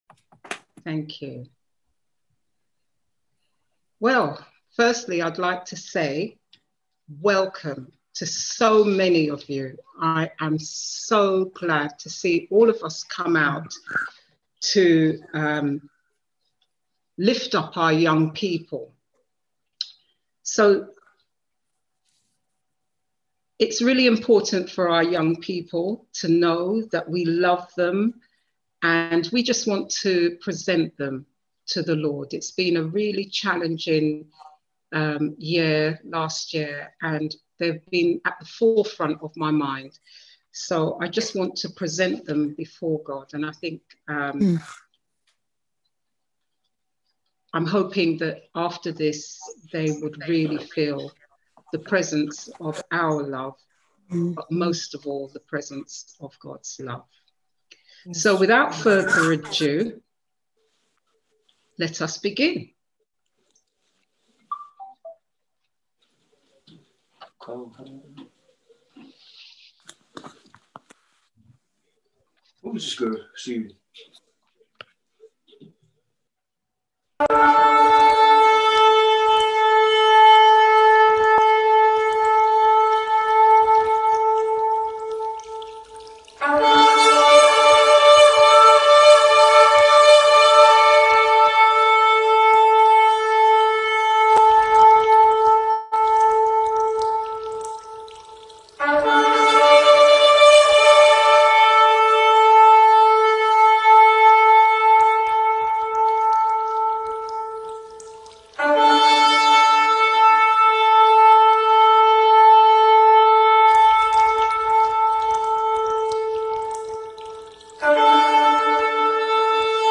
on 2021-02-08 - Redeeming Love Children & Youth Prayer Service 6.2.21